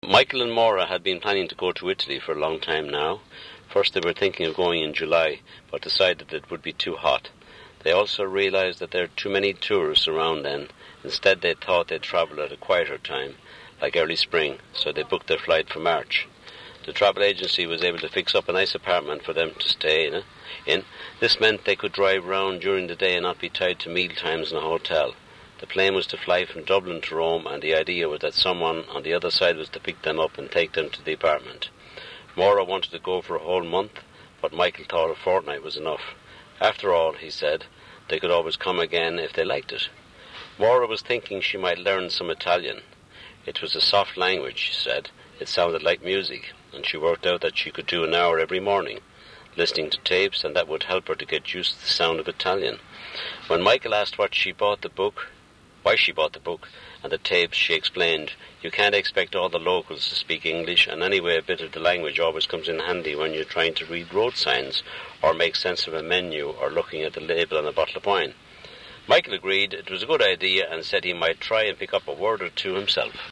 Southern Irish Englis
In pronunciation one can mention the fricative t at the end of words, e.g. cat [kæṱ], and the stop realisation of dental fricatives, e.g. think [ḏɪŋk]. Vowels are also different: there is much less diphthongisation and the vowel in cancel is central and not retracted, i.e. [ka:nsḷ].
Ireland_South_General.wav